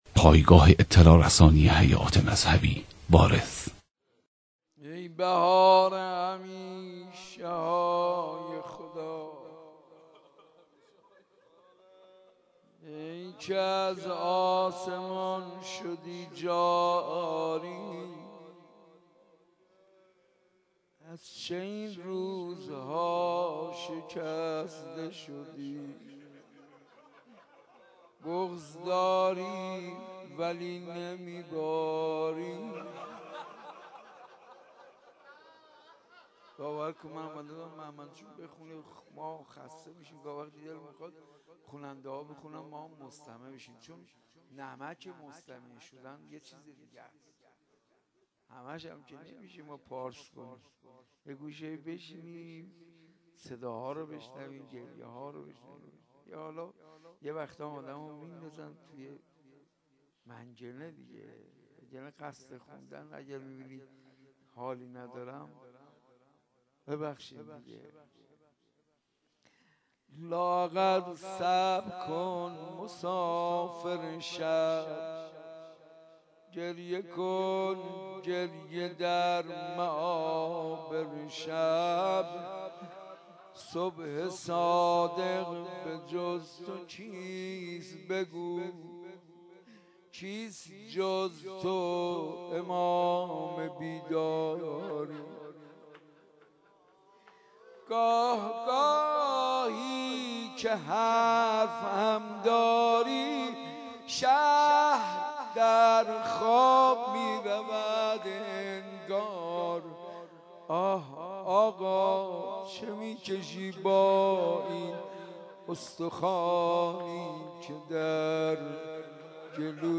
مداحی حاج منصور ارضی به مناسبت شهادت امام صادق (ع)